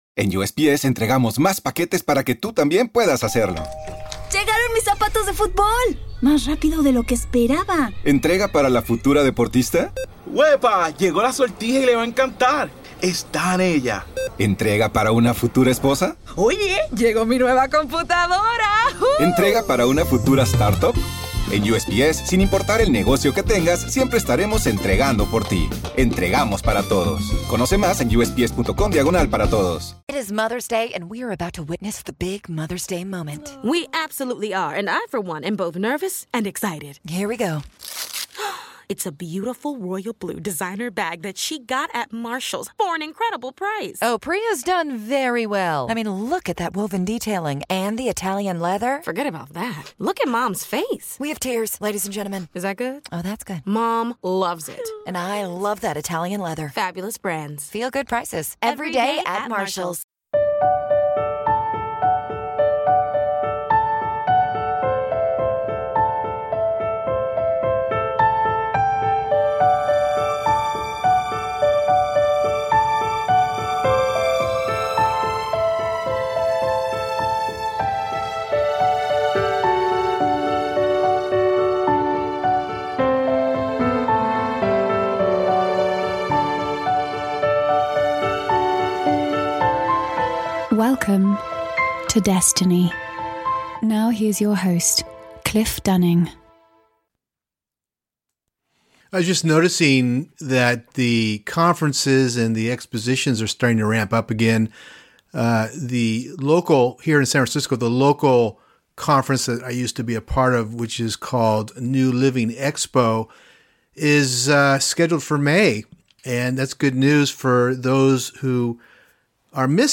Destiny Podcast Interview